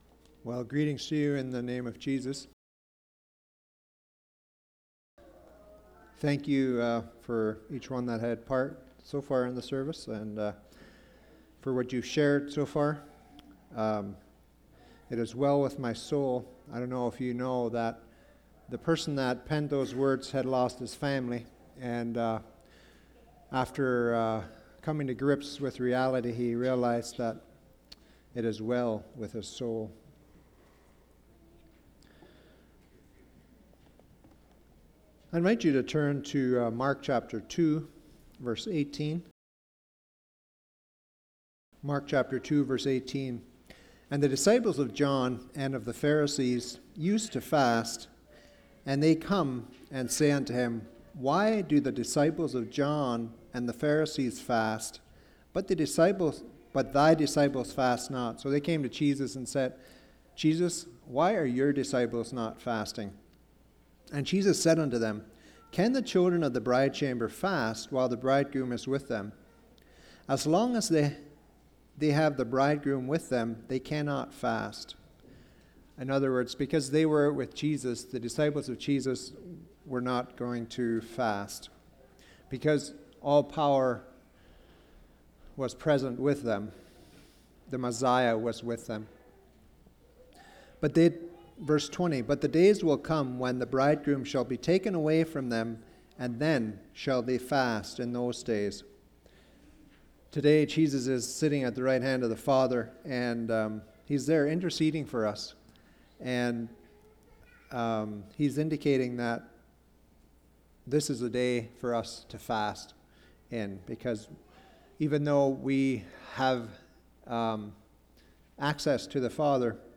Service Type: Sunday Sermons